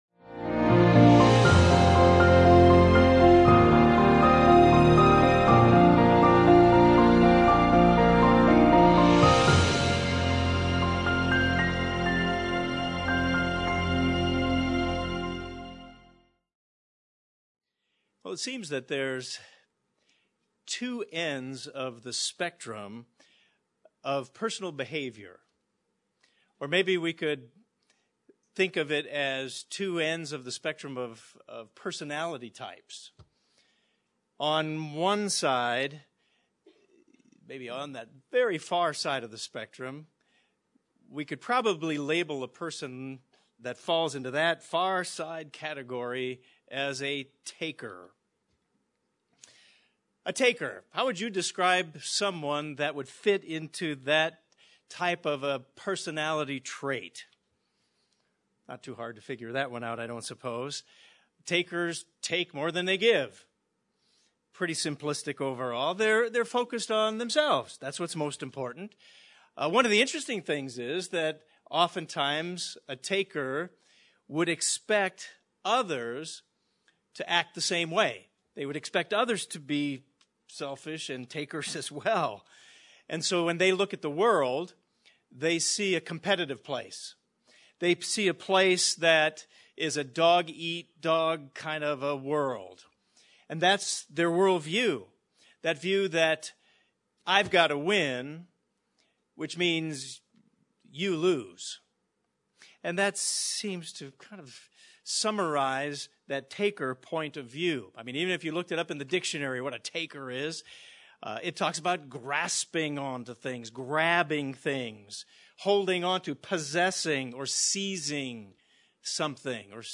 In this sermon we will look at several things we should consider in our lives that can help us figure out if we are a giver or a taker.